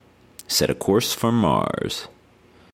描述：与科幻相关的口头文本样本。
Tag: 语音 英语 科幻 美国航空航天局 电火花 声乐 口语 空间